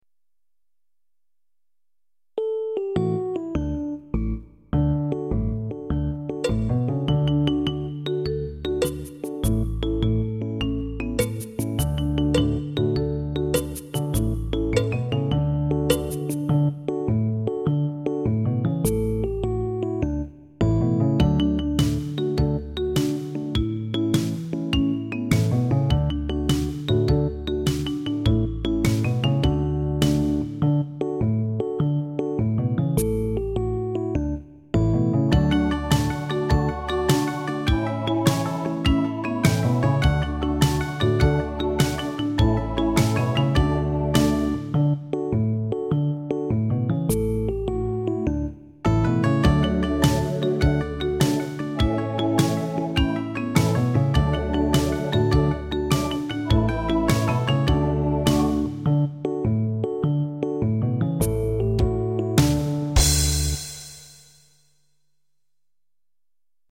Traditional songs